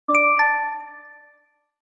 SMS Alert
Sound Effect LINE Notification